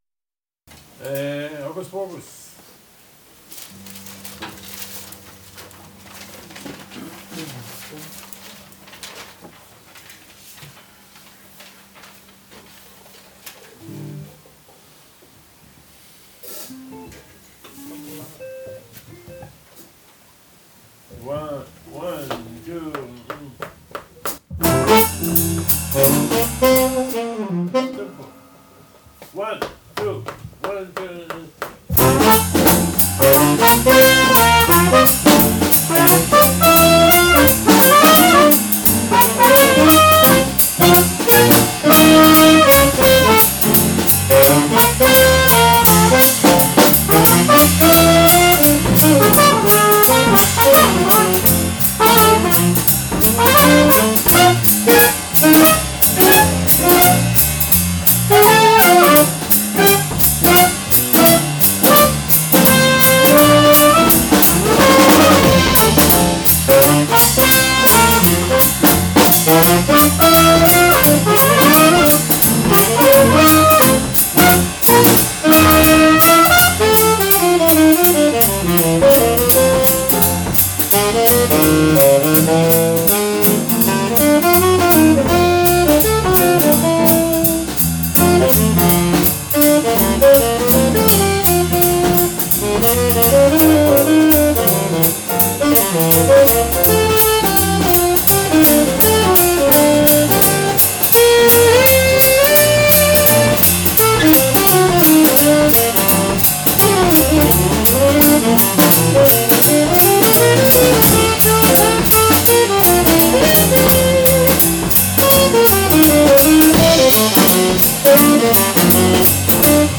Playing ensemble 30.11.10 - hocus pocus.mp3